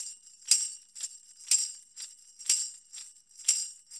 Pandereta_ ST 120_6.wav